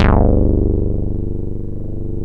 MOOGBASS1 G1.wav